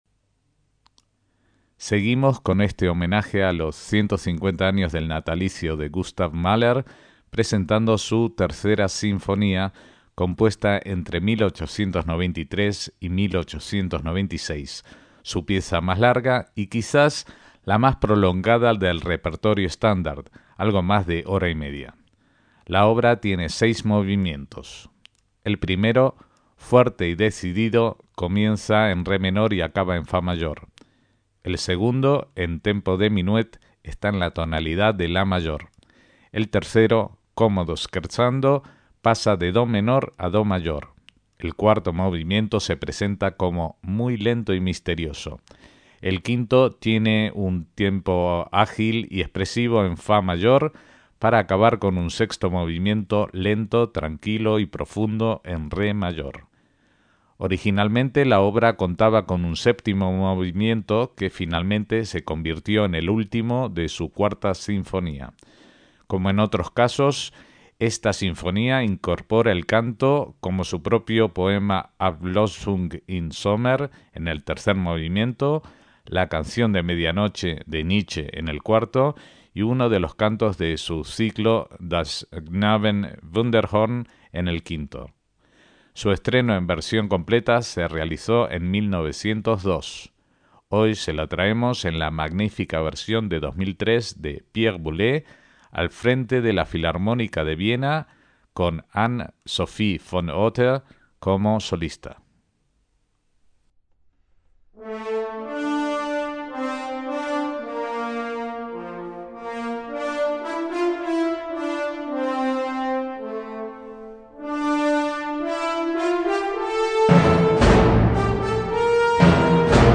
re menor
sinfonía coral